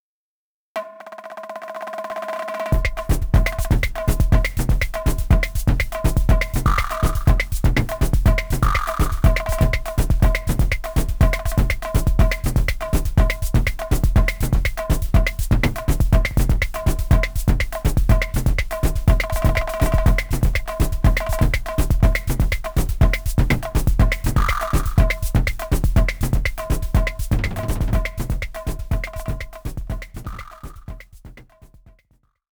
I had a go at guiro, tablas and some toms.
For the guiro I used Sy bits with a lot of SRR, ramp LFO to volume, second LFO to speed.
Toms are SY Chord, tablas are SY Bits.